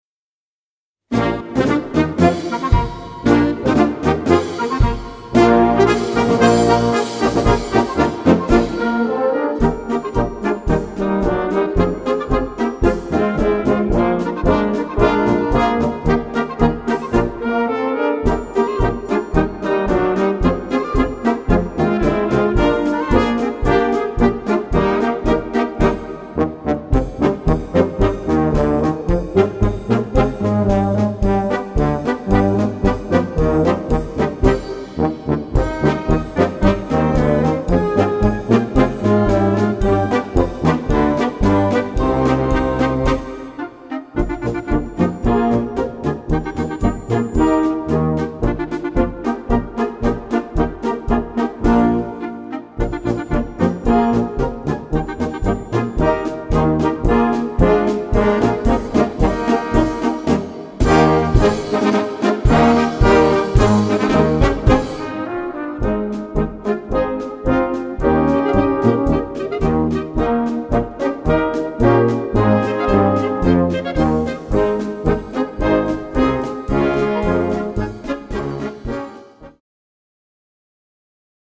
Gattung: Young Band March
A4 Besetzung: Blasorchester Zu hören auf